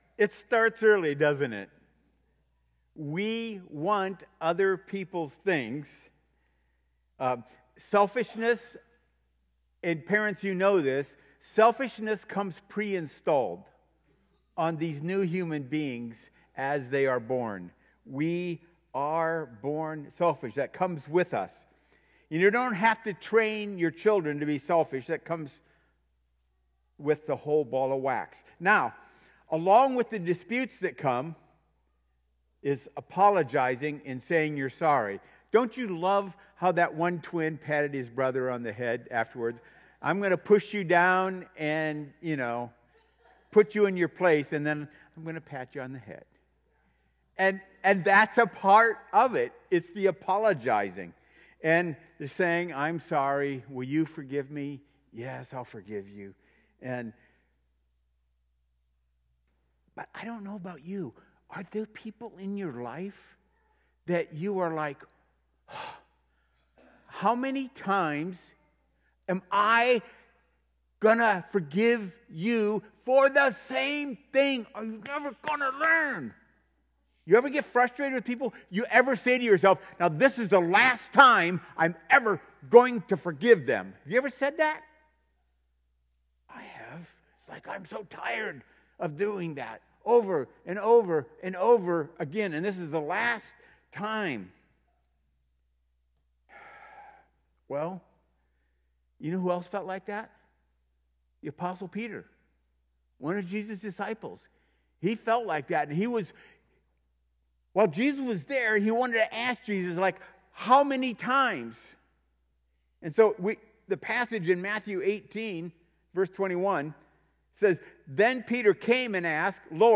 Sermons | Warsaw Missionary Church